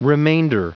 Prononciation du mot remainder en anglais (fichier audio)
Prononciation du mot : remainder